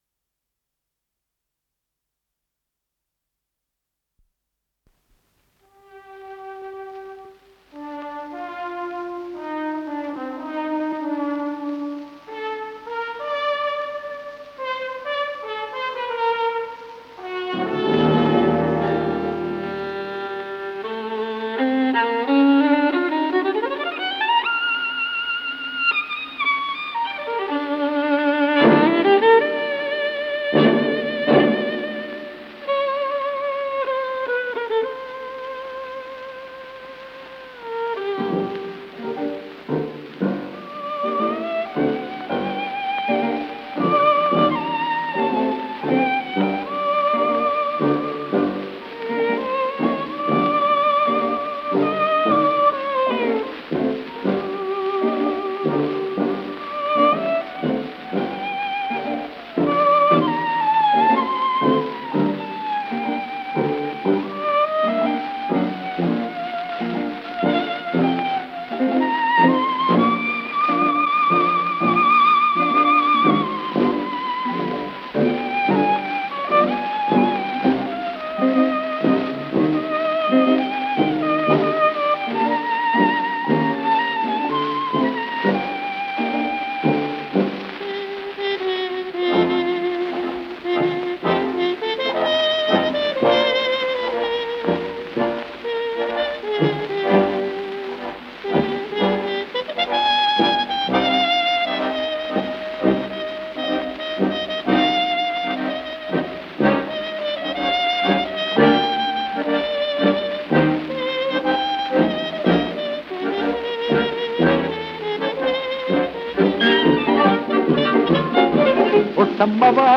с профессиональной магнитной ленты
ПодзаголовокНа русском языке (Запись 30-х годов)